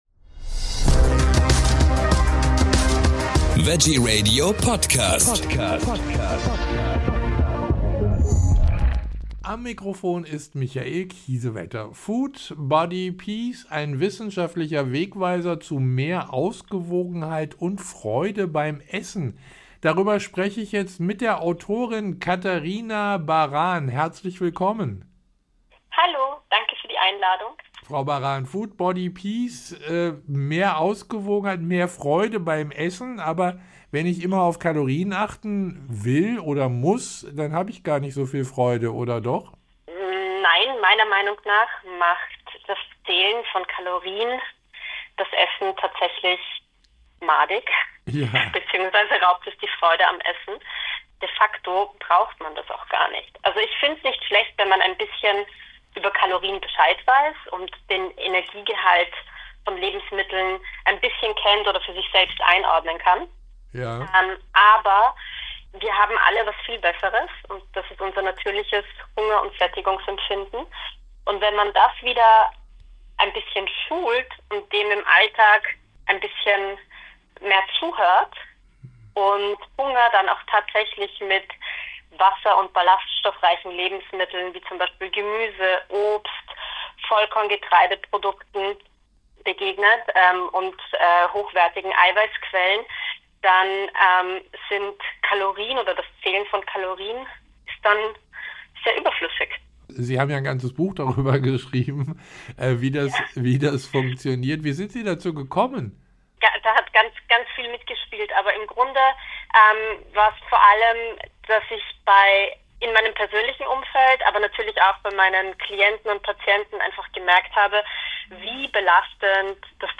Wir haben mit ihr über das